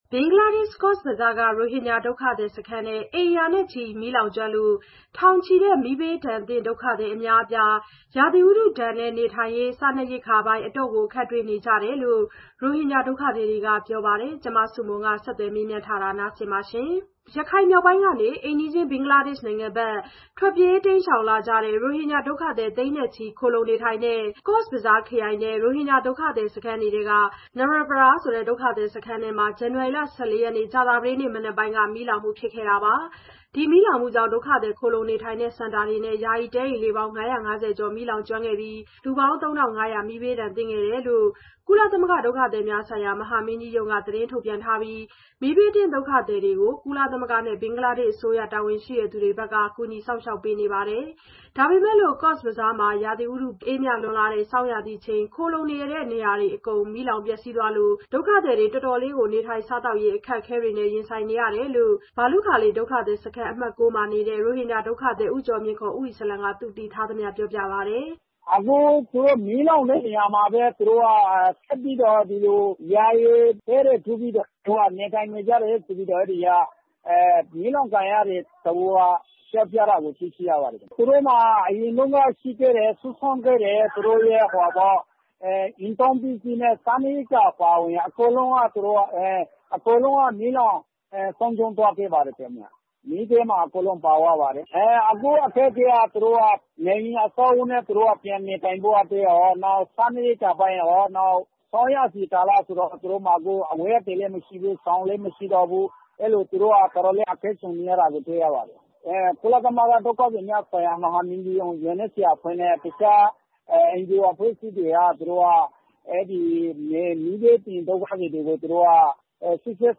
ဆကျသှယျမေးမွနျးထားပါတယျ။